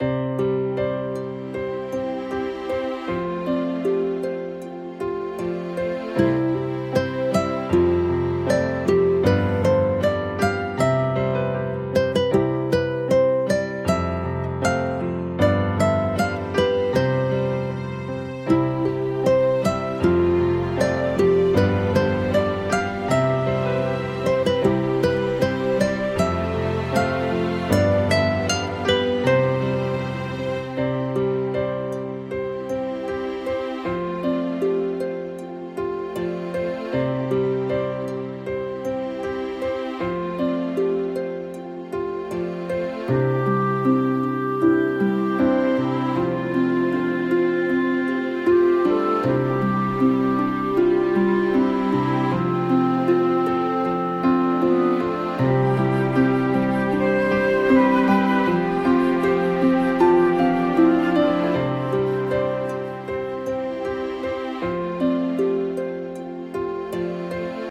抒情的音樂。